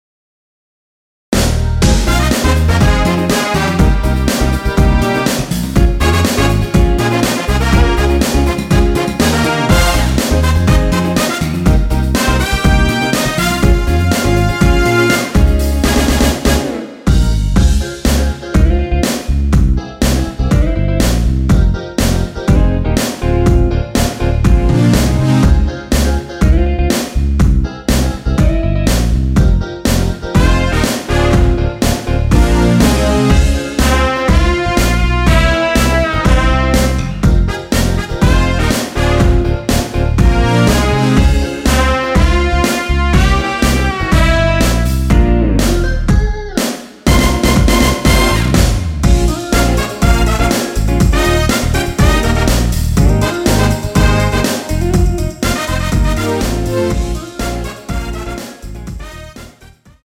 원키에서(-2)내린 MR입니다.
Fm
앞부분30초, 뒷부분30초씩 편집해서 올려 드리고 있습니다.
중간에 음이 끈어지고 다시 나오는 이유는